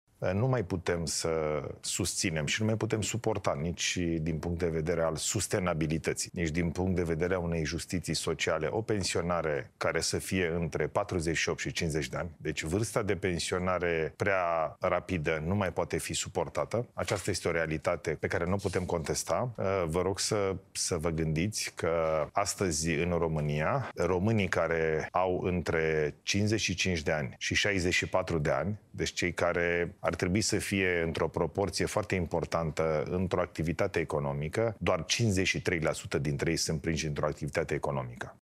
Într-o emisiune la EuroNews România, premierul Ilie Bolojan a afirmat că nu s-a ajuns la niciun acord în urma discuţiilor pe tema reformei pensiilor magistraţilor, care s-au desfăşurat miercuri la Palatul Cotroceni.